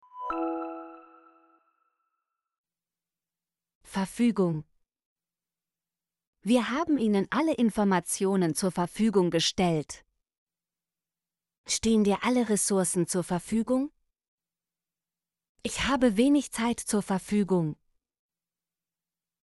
verfügung - Example Sentences & Pronunciation, German Frequency List